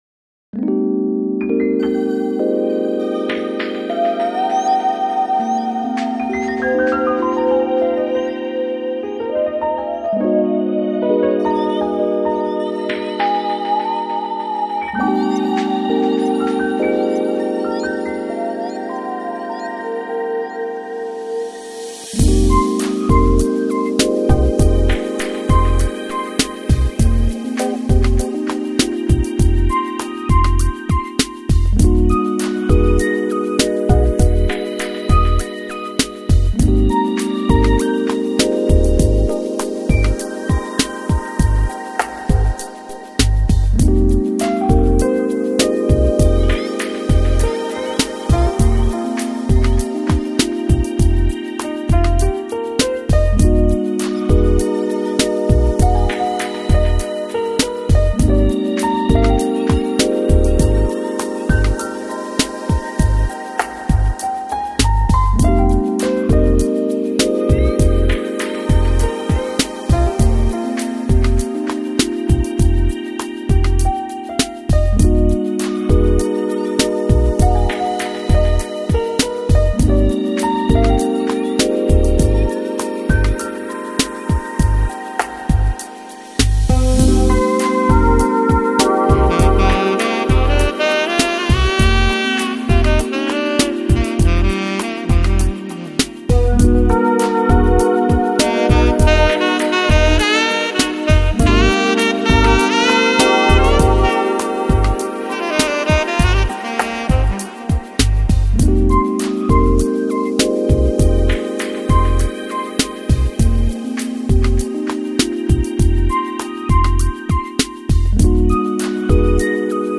Lounge, Chill Out, Smooth Jazz, Easy Listening Год издания